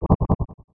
loginfail.wav